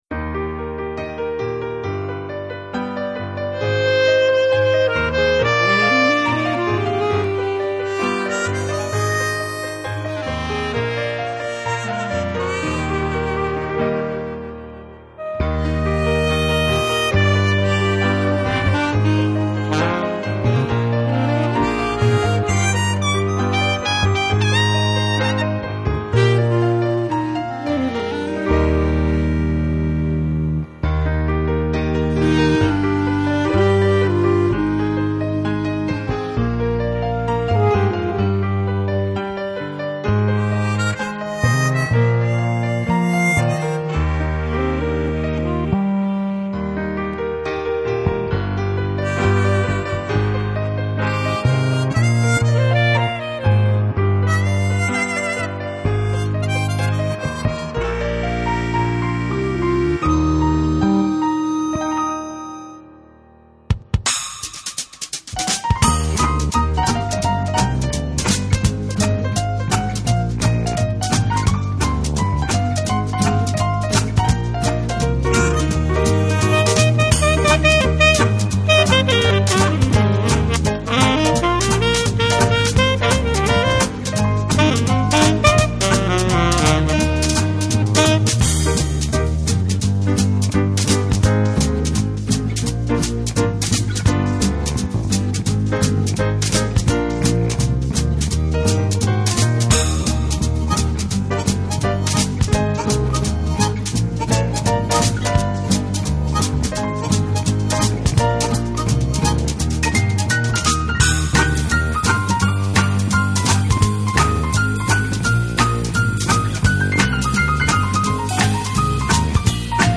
(Karaoké) MP3